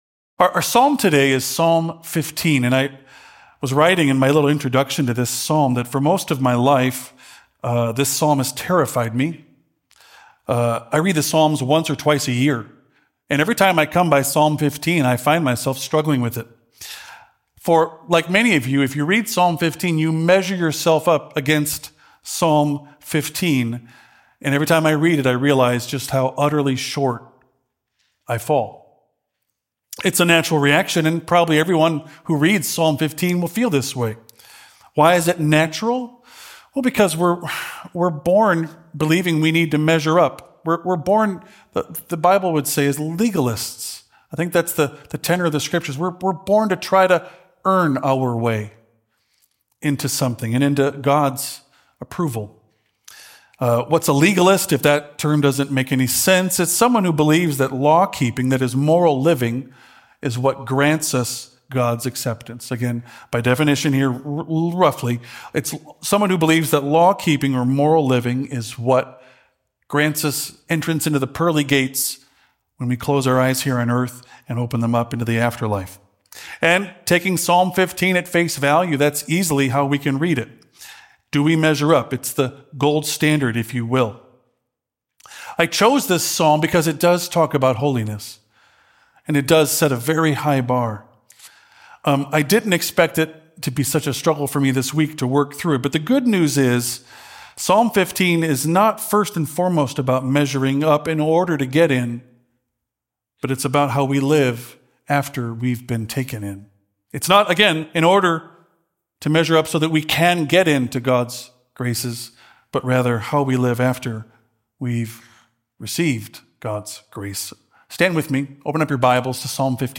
Palm Sunday Sermon